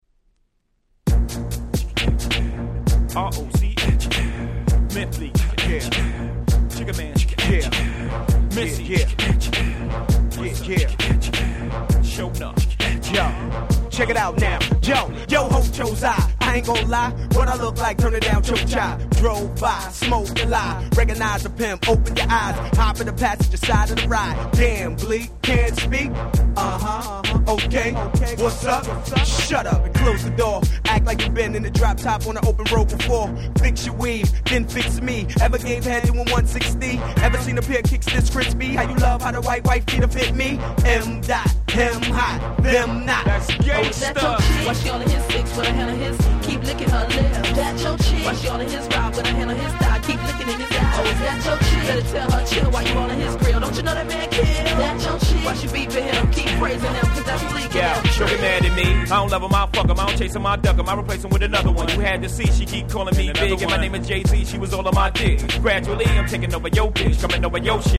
00' Smash Hit Hip Hop !!